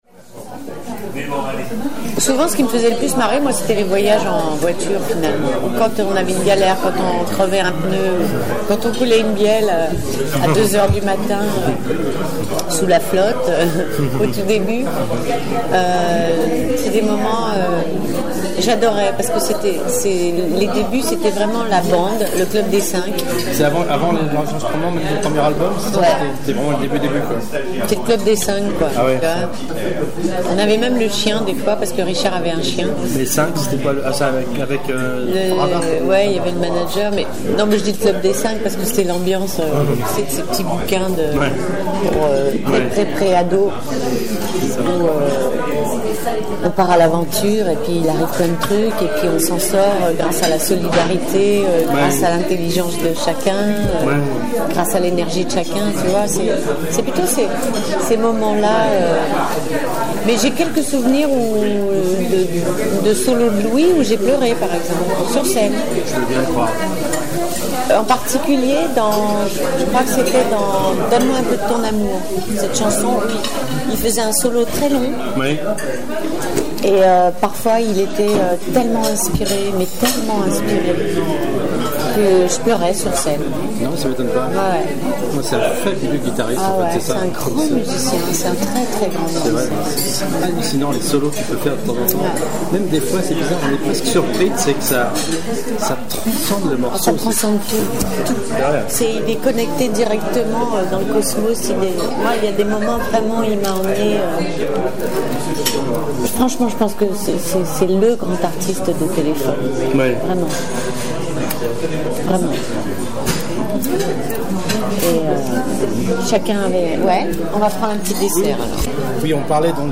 Extraits d'interviews de Corine menées pour la biographie de Téléphone...